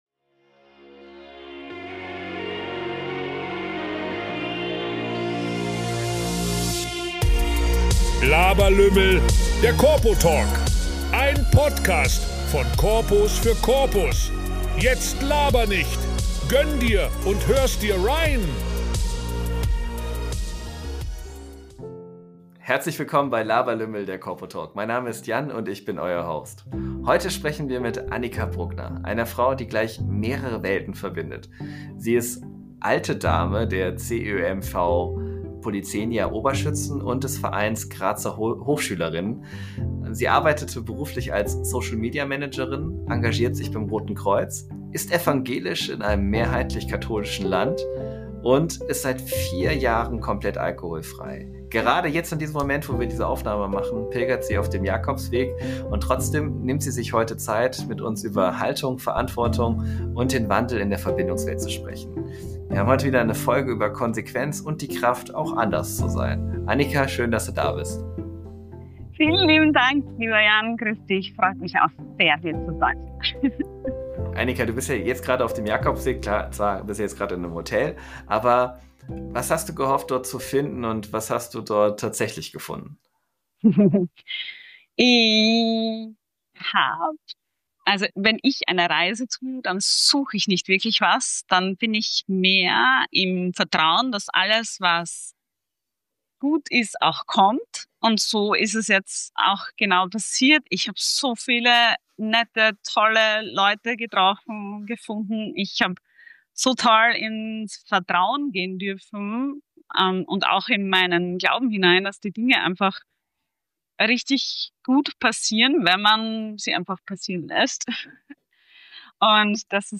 Ein tiefgründiges Gespräch über Haltung, Wandel und die traditionsreiche Vielfalt der Verbindungswelt.